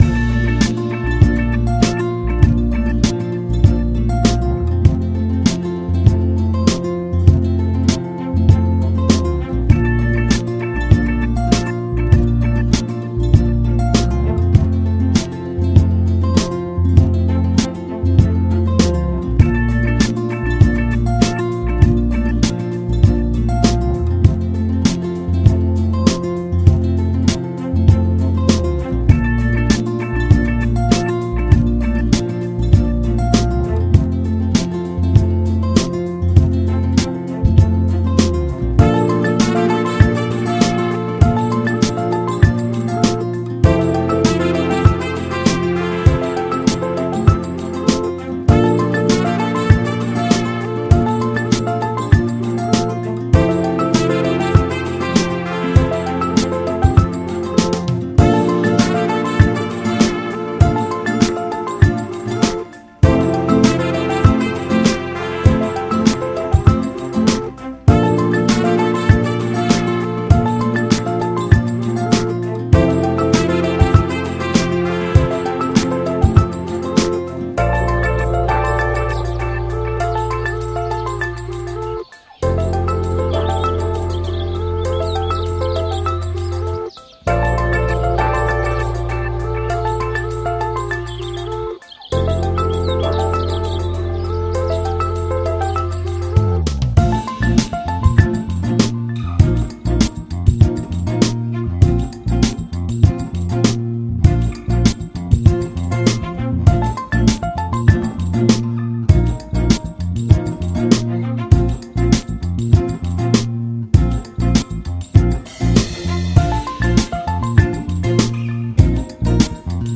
Звук умиротворяющей и приятной музыки для фона